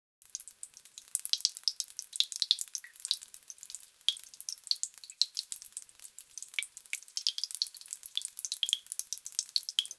SFX: Rain dripping - drip... drop...]
sfx-rain-dripping---drip-njupgsl5.wav